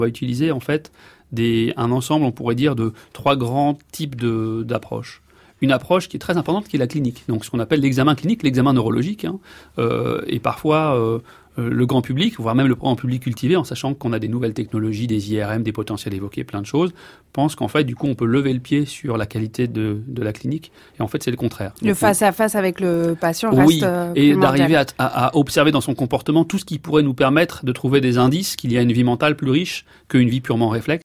J’ai pensé que cette mise au point valait bien un article après avoir entendu Lionel Naccache qui est l’un des plus grands neuroscientifiques actuels et qui s’exprimait ainsi.
naccache-examen-clinique.mp3